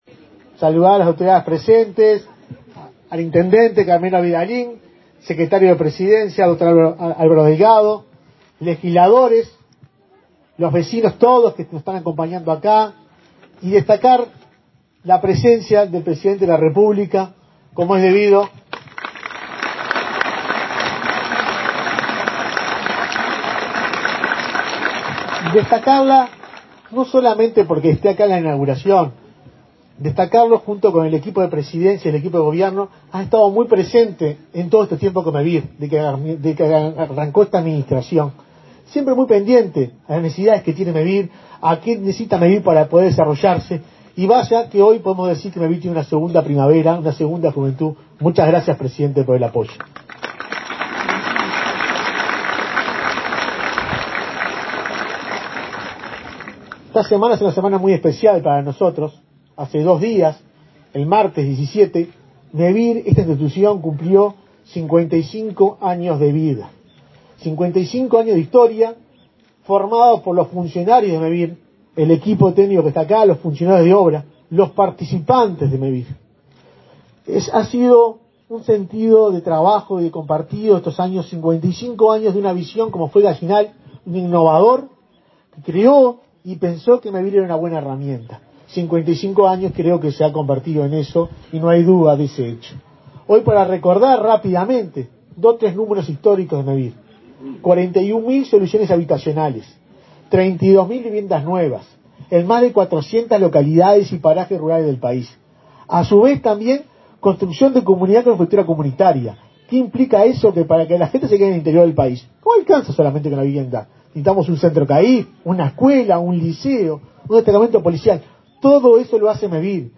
Palabras del presidente de Mevir, Juan Pablo Delgado
Palabras del presidente de Mevir, Juan Pablo Delgado 19/05/2022 Compartir Facebook Twitter Copiar enlace WhatsApp LinkedIn Con la presencia del presidente de la República, Luis Lacalle Pou, Mevir inauguró, este 19 de mayo, 39 soluciones habitacionales en la localidad de Blanquillo, departamento de Durazno. Delgado participó en el acto.